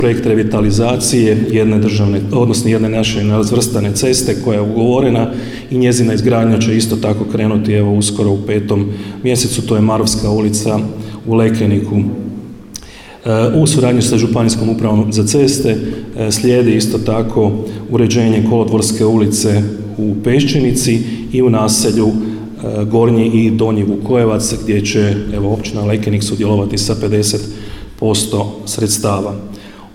Obilježba je zaključena prigodnim programom u Društvenom domu i turističko-informativnom centru u Lekeniku, gdje su načelnik Ivica Perović i sada već bivši predsjednik Općinskog vijeća Marin Čačić dodijelili javna priznanja Općine Lekenik u 2025. godini.